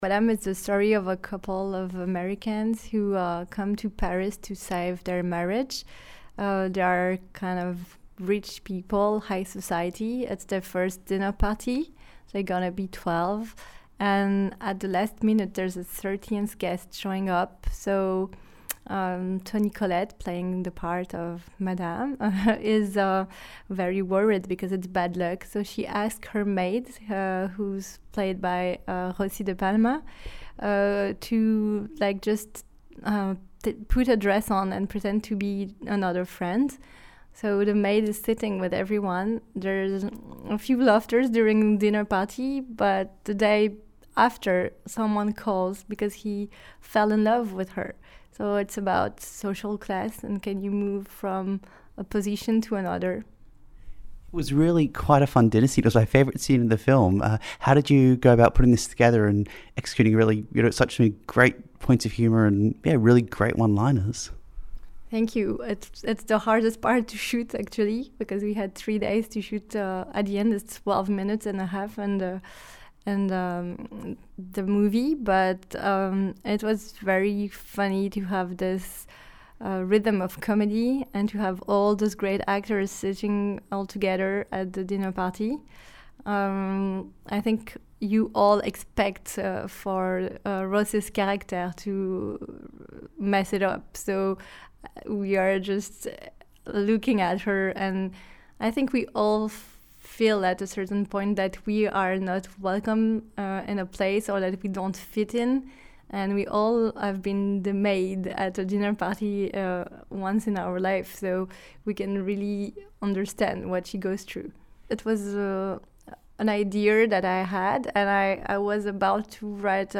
Festivals, Interviews